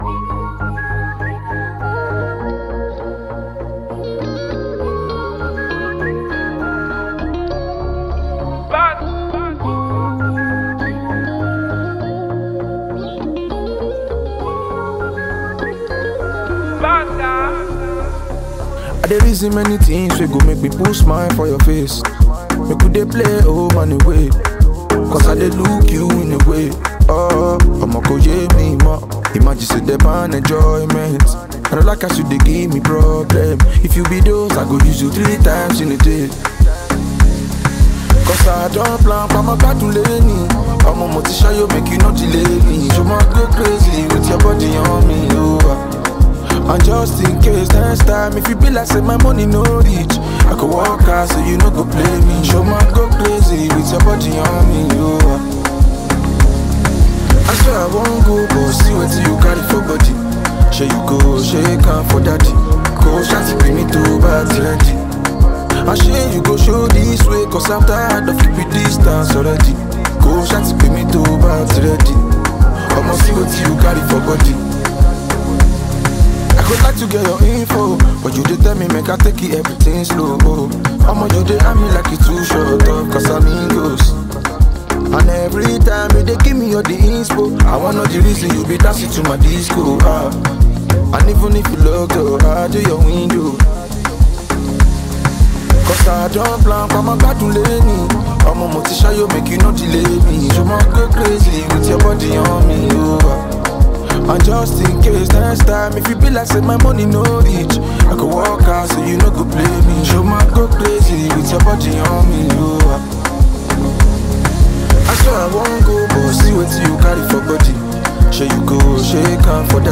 electrifying single